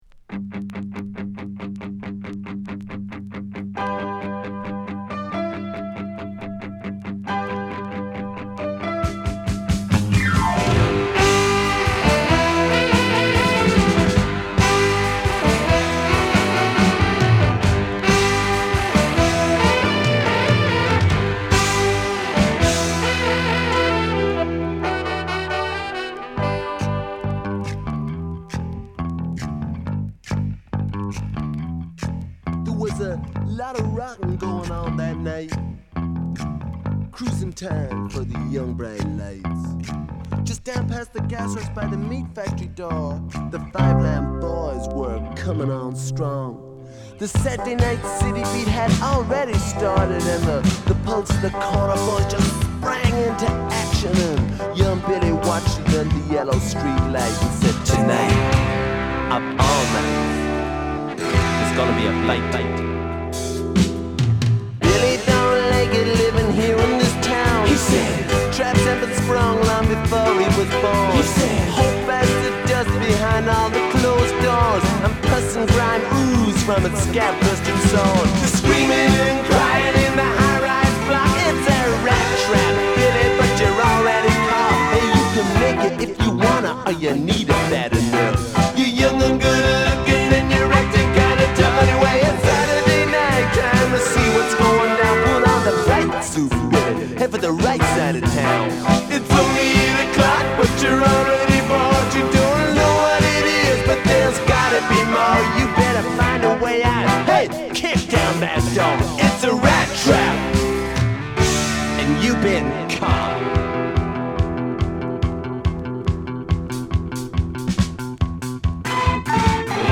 as well as the first 'New Wave' song to do likewise.